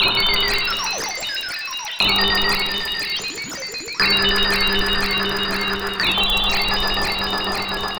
Interia Synth.wav